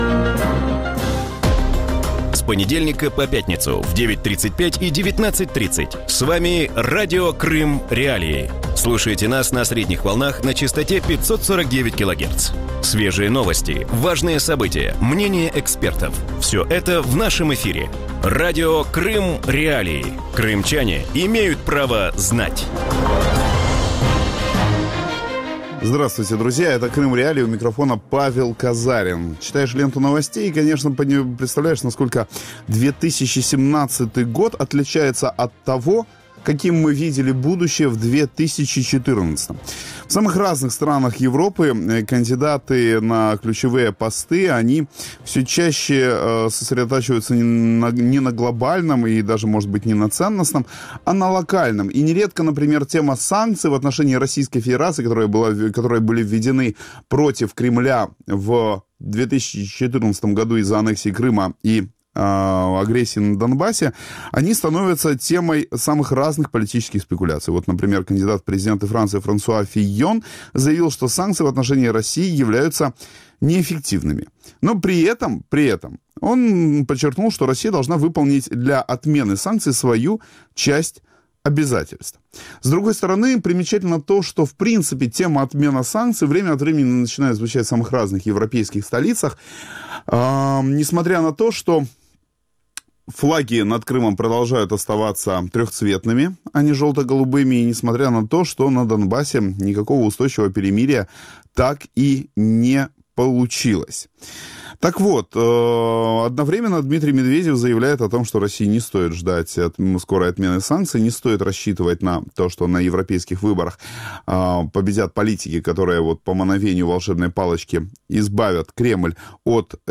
В вечернем эфире Радио Крым.Реалии говорят о скепсисе российских чиновников насчет отмены антироссийских санкций. Привыкли ли Россия и Крым к санкционному давлению, как ограничения влияют на российскую экономику спустя три года после их введения и какой политики в отношении России можно ожидать от новых мировых лидеров?